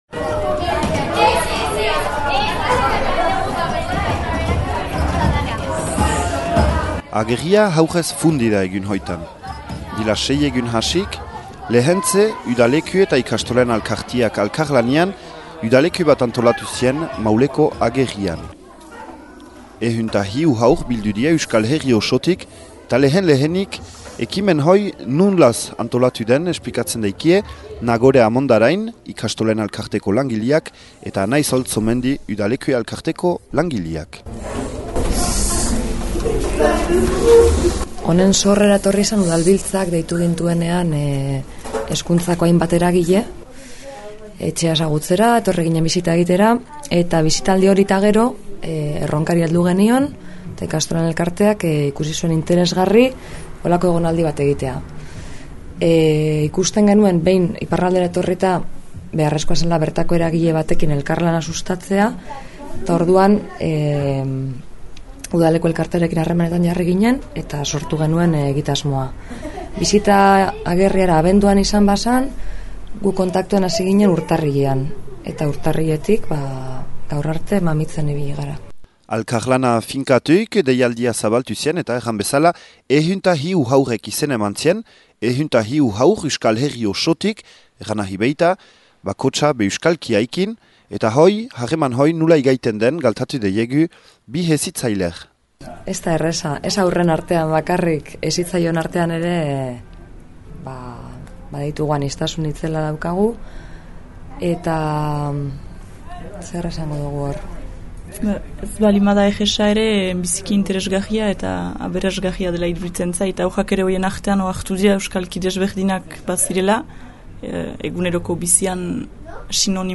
Entzün Agerrian egin dügün erreportajea: